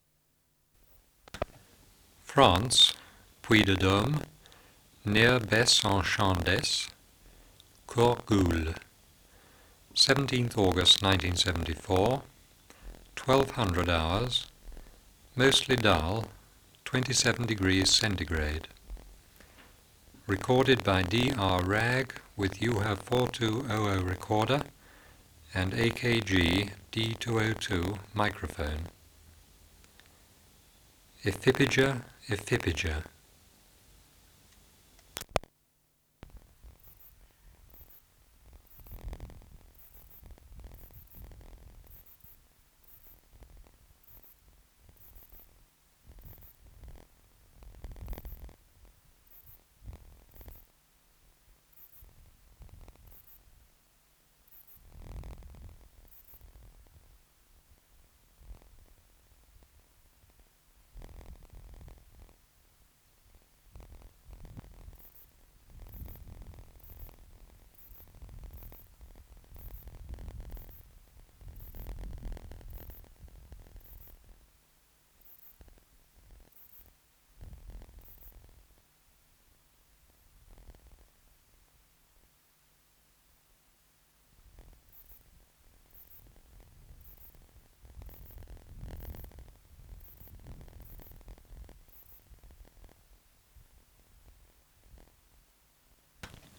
131_2 Ephippiger ephippiger | BioAcoustica